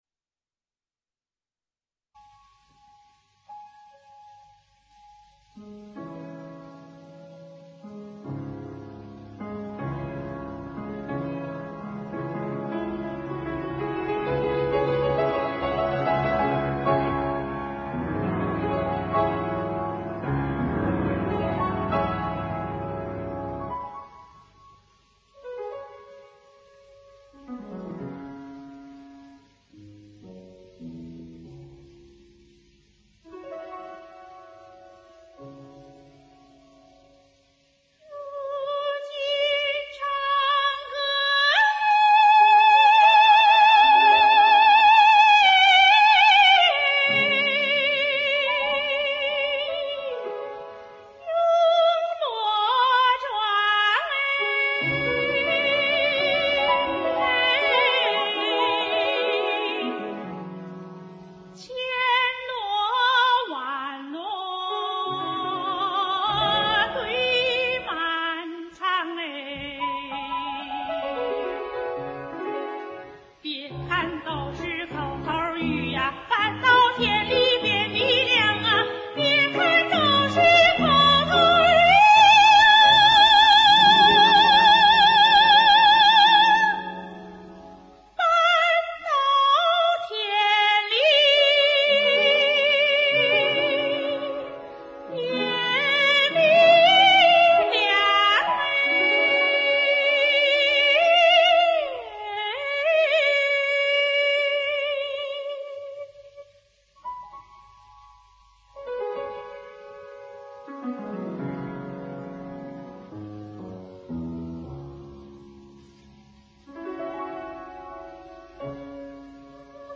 女高音歌唱家。